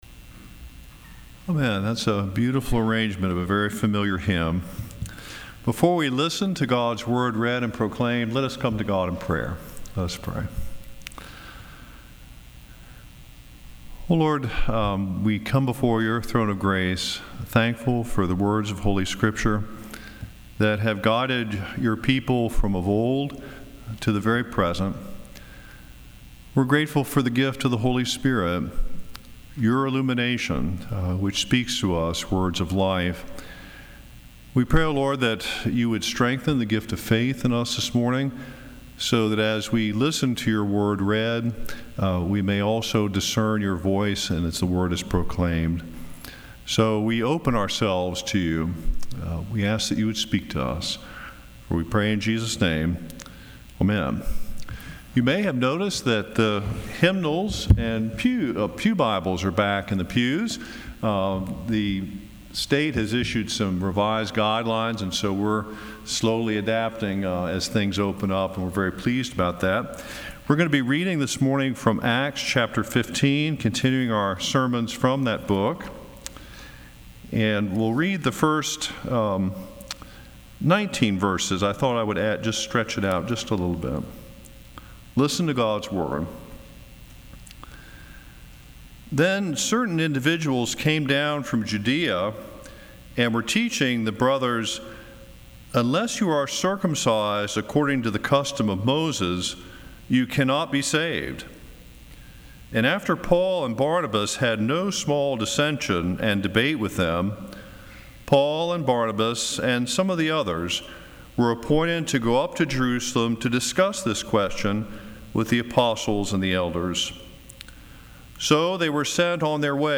Acts 14:1-18 Service Type: Sunday Morning Decisions